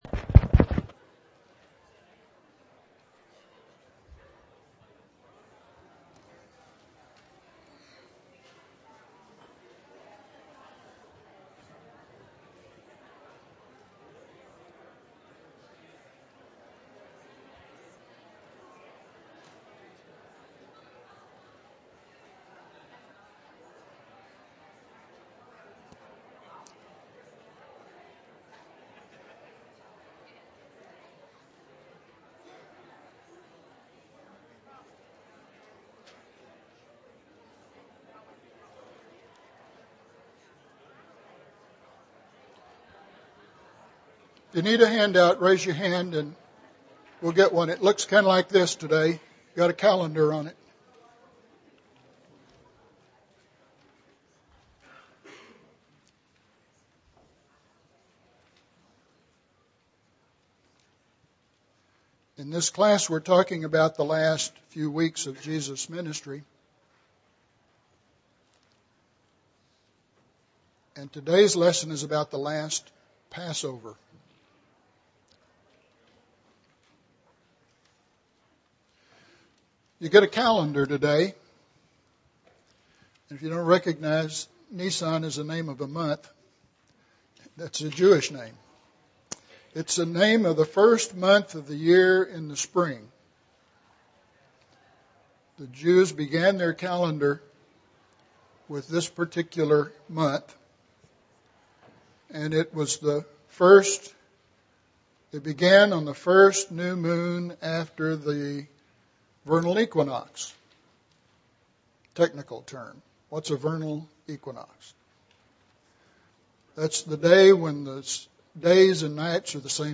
The Last Passover (6 of 9) – Bible Lesson Recording
Sunday AM Bible Class